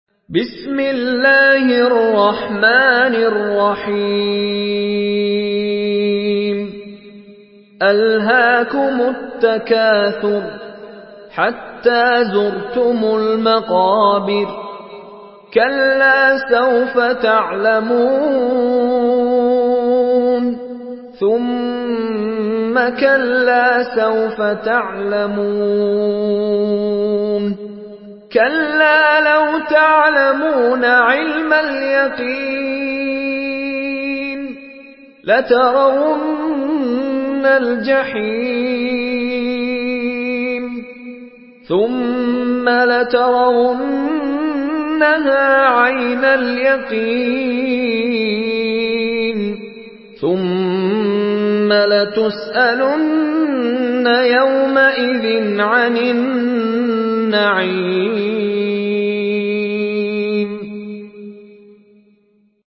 سورة التكاثر MP3 بصوت مشاري راشد العفاسي برواية حفص
مرتل حفص عن عاصم